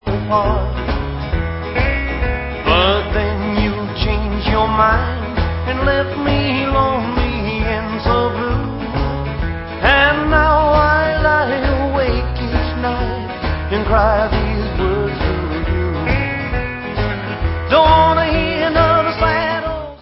Counrty swing with rockabilly flavors